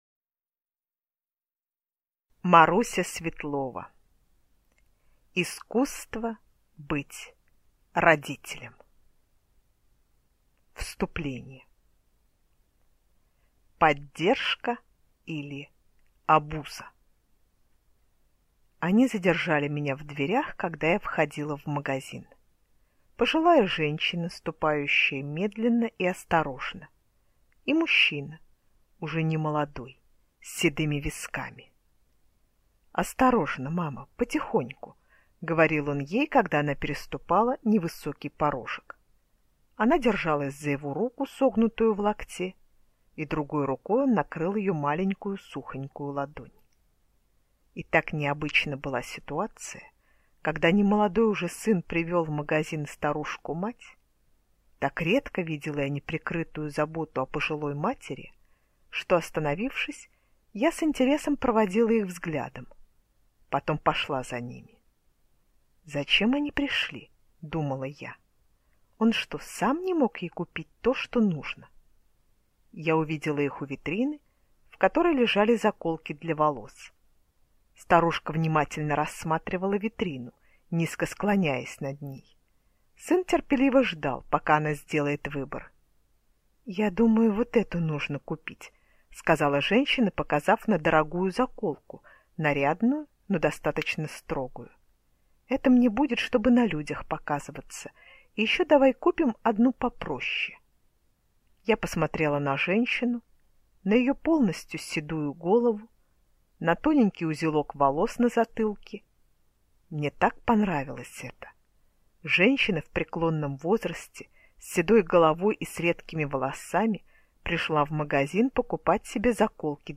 Аудиокнига Искусство быть родителем | Библиотека аудиокниг